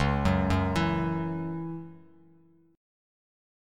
C#mbb5 chord